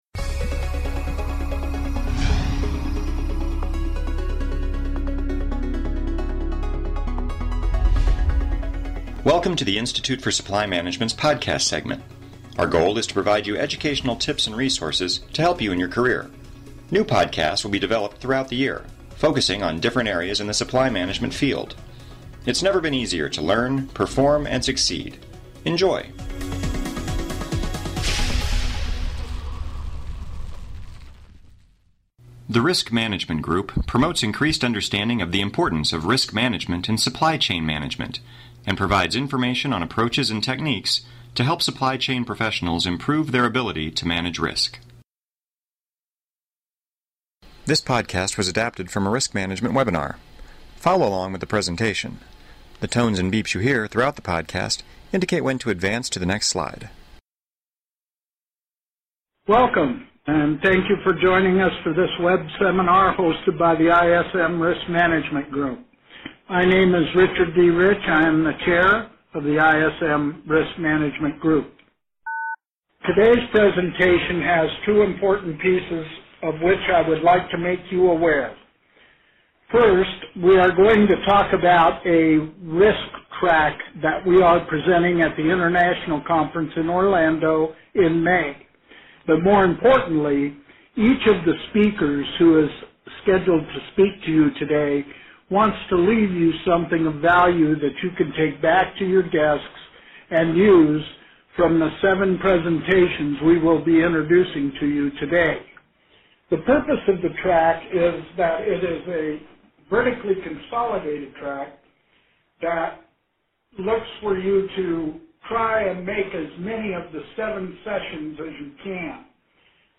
Title: Risk Management Overview: Adapted from a Risk Management Group webinar. Risk Management Webinar Presentation Length: 35 minutes Type: Panel Please click below to take a brief survey on this podcast.